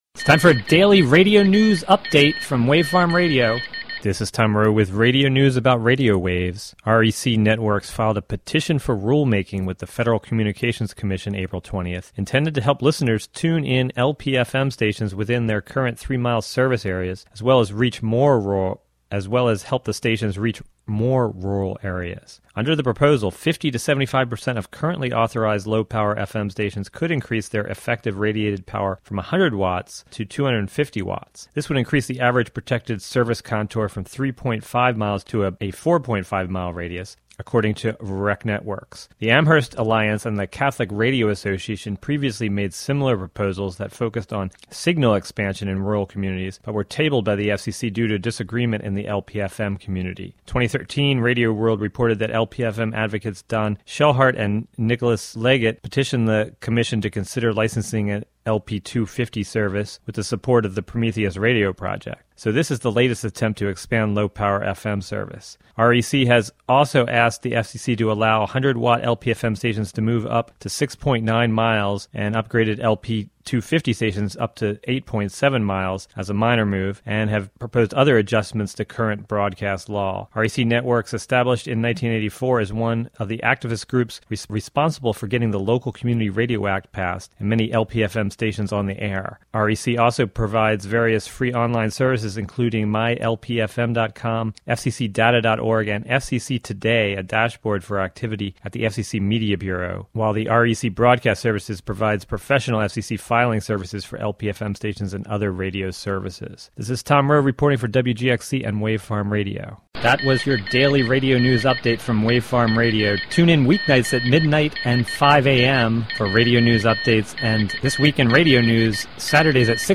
Report about a proposal before the FCC to allow many LPFM stations to enpand to 250 watts.